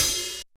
Hat (66).wav